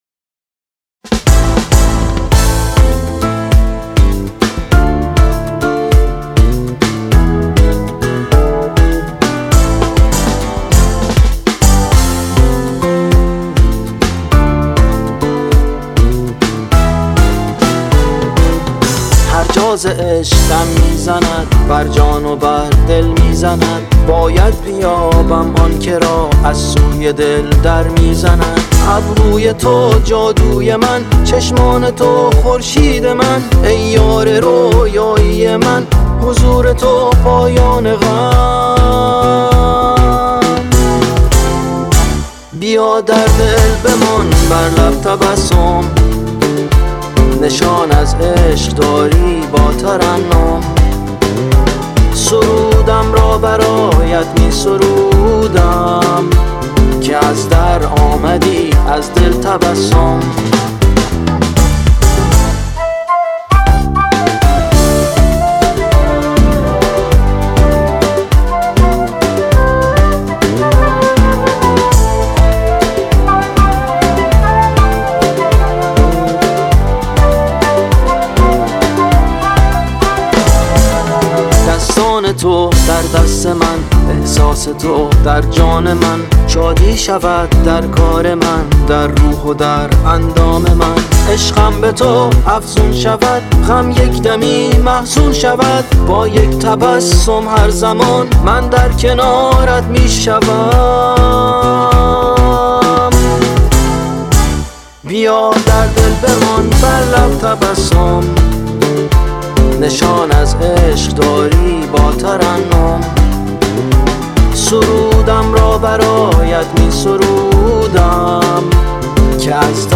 🎸گیتار باس